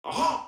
VO_LVL3_EVENT_Aha echec_01.ogg